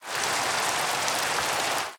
Minecraft Version Minecraft Version snapshot Latest Release | Latest Snapshot snapshot / assets / minecraft / sounds / ambient / weather / rain6.ogg Compare With Compare With Latest Release | Latest Snapshot
rain6.ogg